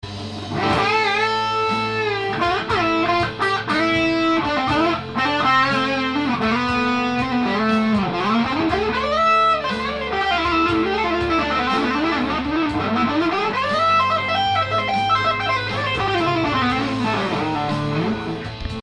ギターをリペア後のサウンドチェックをしてみました♪
試しにサウンドチェックをしてみました。
そして、テンションが緩いので速弾きもしやすくいつもの１,5倍ぐらいの速さで速弾きが出来ます。